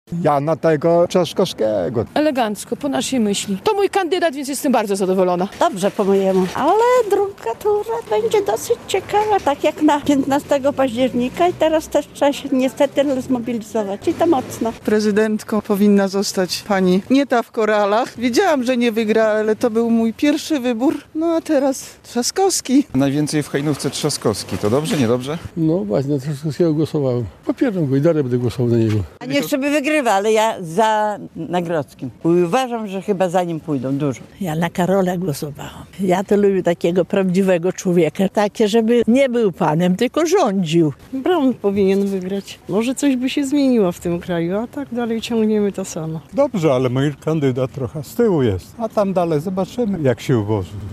Trzaskowski wygrywa w powiecie hajnowskim - relacja